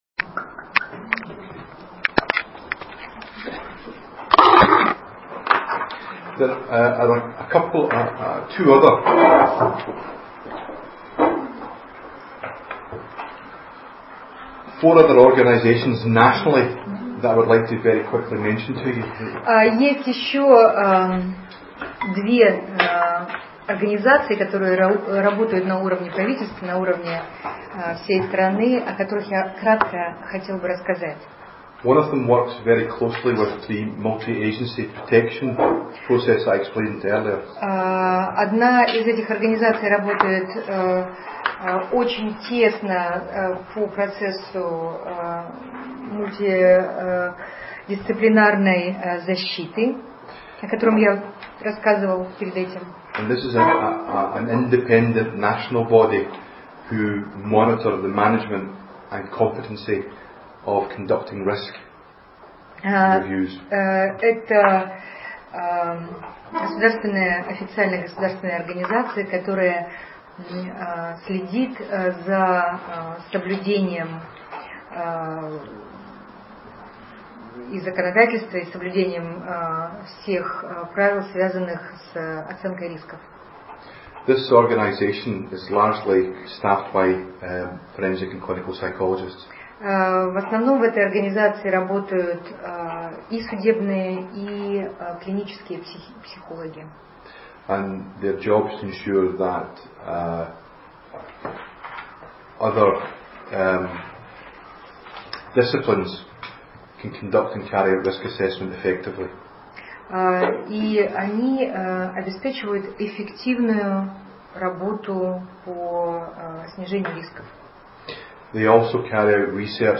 семинар
Екатеринбург